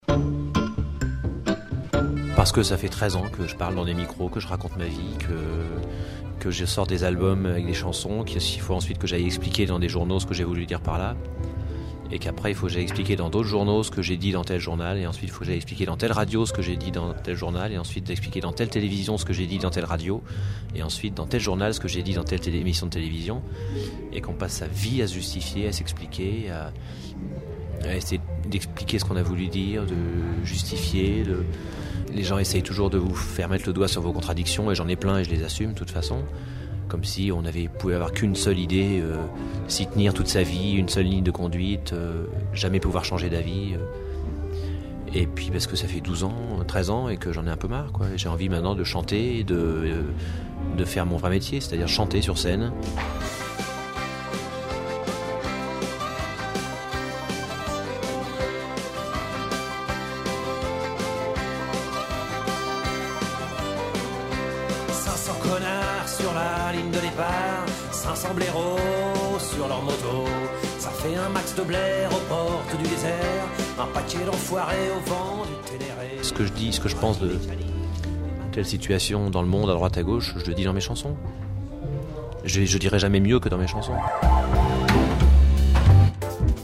• Interviews de Renaud sur RTS